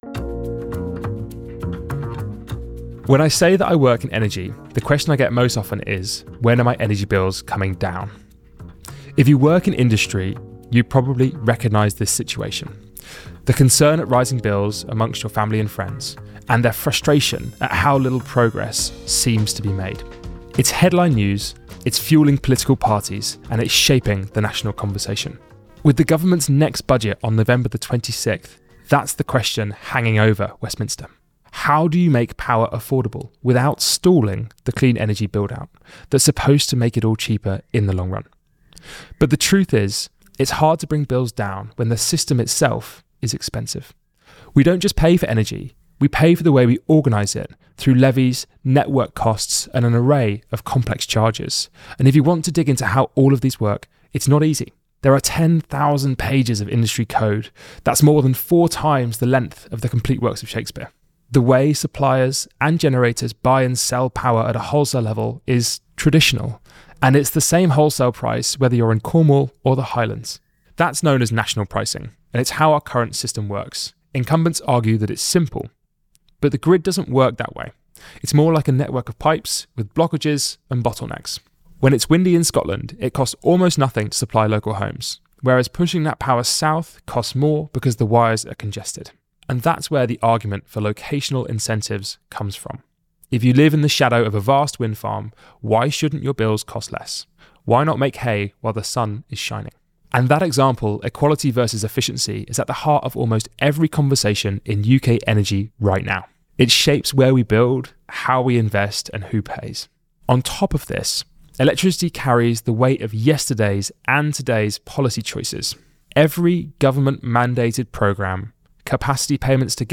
The discussion brings clarity to one of the transition’s biggest challenges: making the energy system work for everyone.